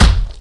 Kick7.wav